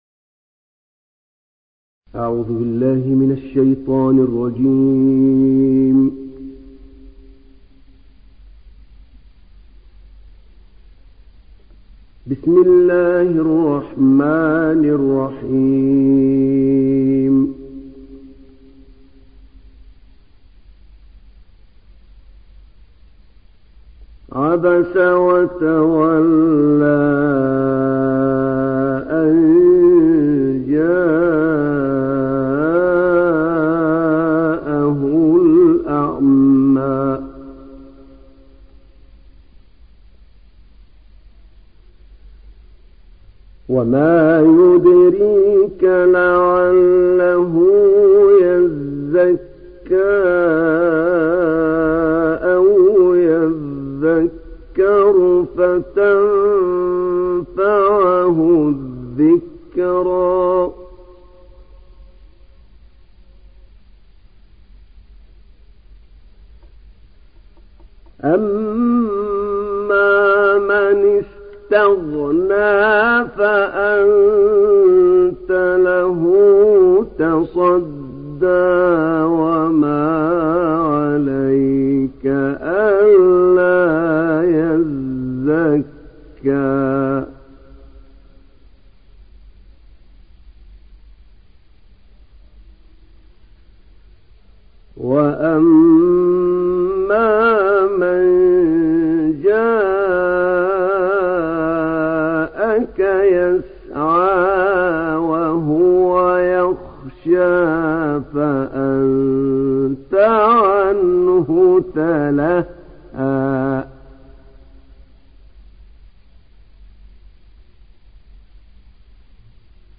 تحميل سورة عبس mp3 بصوت أحمد نعينع برواية حفص عن عاصم, تحميل استماع القرآن الكريم على الجوال mp3 كاملا بروابط مباشرة وسريعة